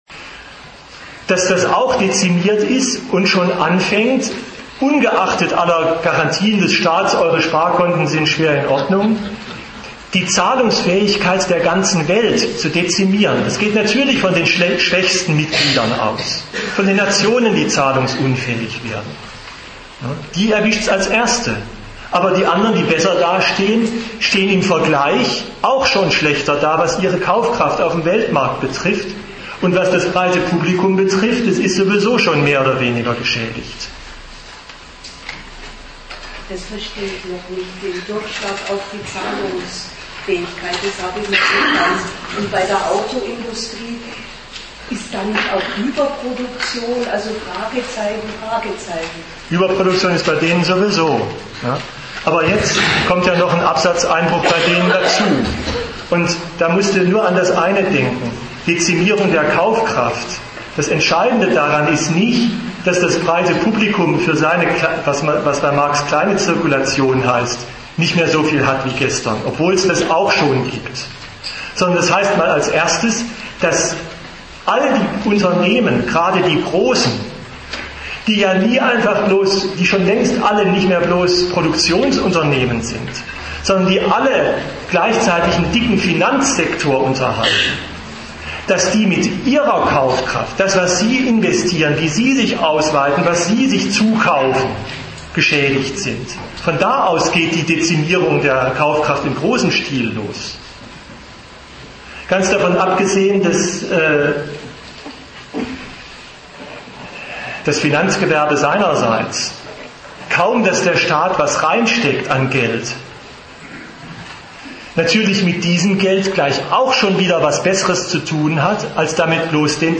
Ort München